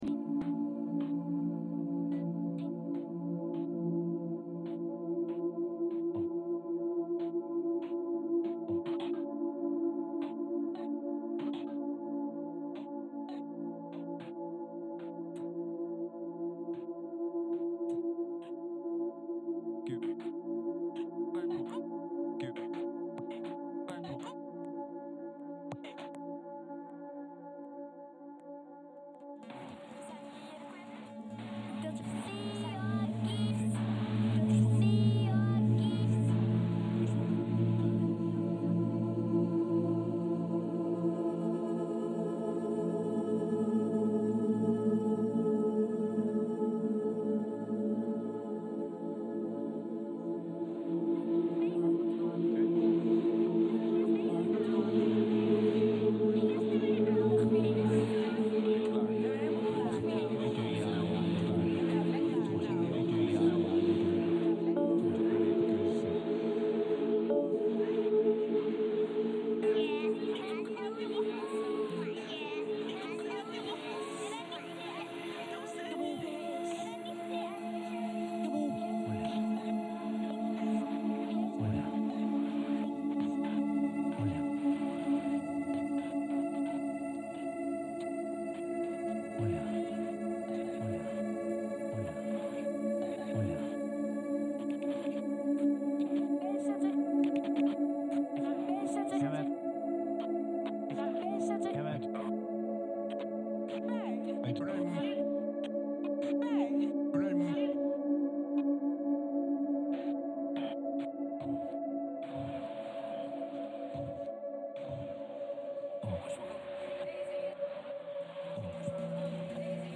The installation runs on Mac mini with Ableton Live (+ several modified Max for Live devices) and a shell script that automatically receives the recorded files and sorts them.
Using a modified version of Dillon Bastan’s Coalascence each time someone speaks to the microphone it creates adds a recording to the growing archive of voices that feeds a neural network that chops the samples and organises the slices based on their similarity.
Site-specific sound installation.